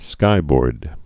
(skībôrd)